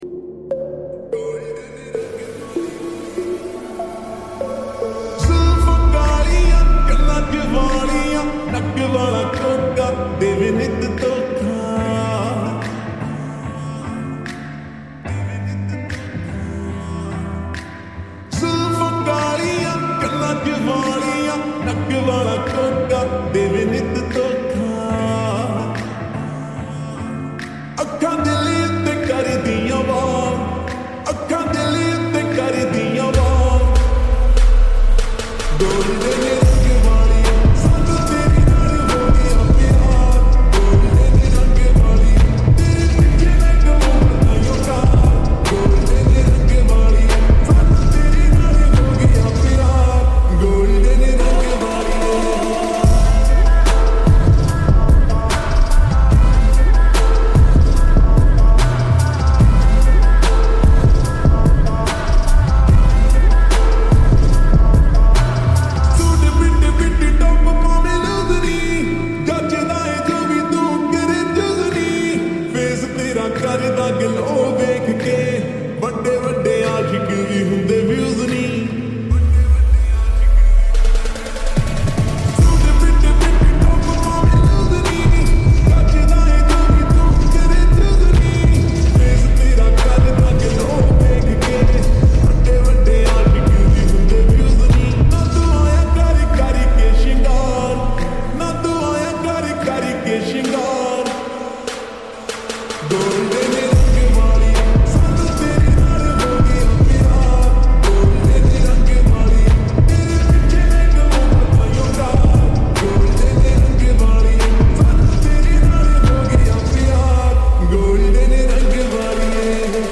Slowed And Reverb
Old romantic song